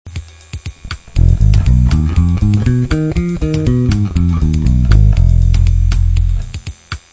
Cliquer sur Ex et vous  aurez un exemple sonore en F (Fa).
m7b5.wav